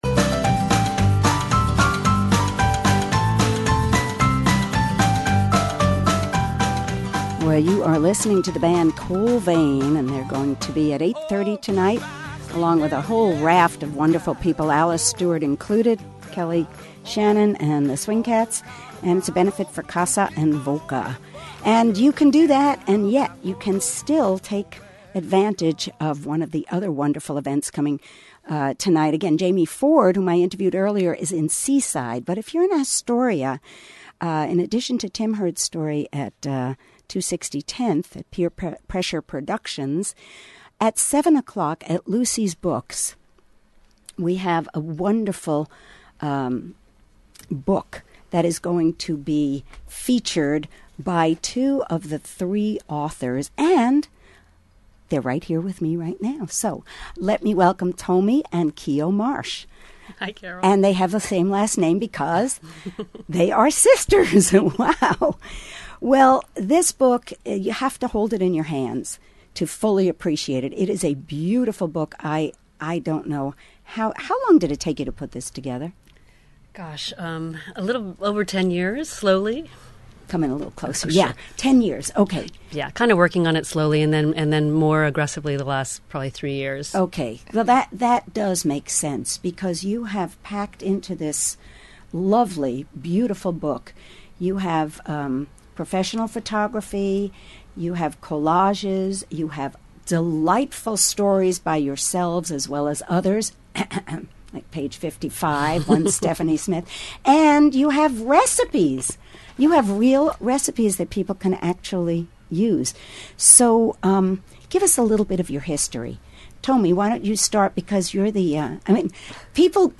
KMUN Interview New Day Northwest (video)